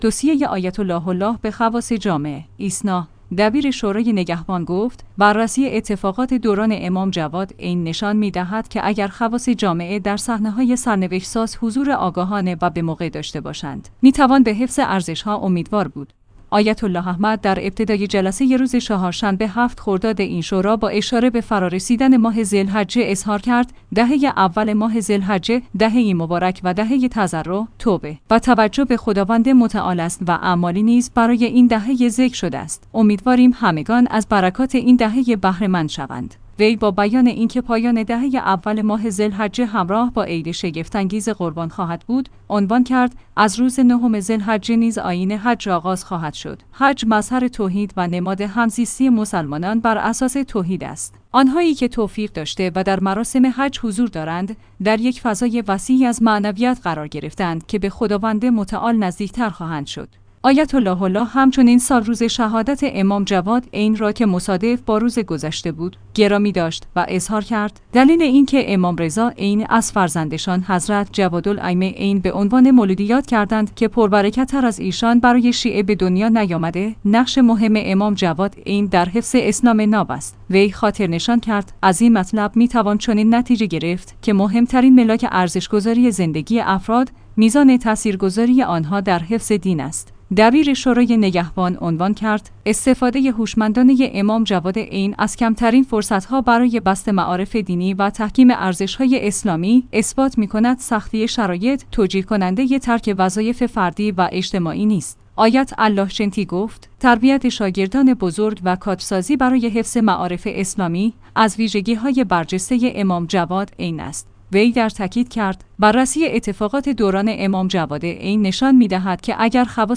ایسنا/ دبیر شورای نگهبان گفت: بررسی اتفاقات دوران امام جواد (ع) نشان می‌دهد که اگر خواص جامعه در صحنه‌های سرنوشت‌ساز حضور آگاهانه و به‌موقع داشته باشند، می‌توان به حفظ ارزش‌ها امیدوار بود. آیت‌الله احمد جنتی در ابتدای جلسه روز چهارشنبه ۷ خرداد این شورا با اشاره به فرارسیدن ماه ذی‌الحجه اظهار کرد: ده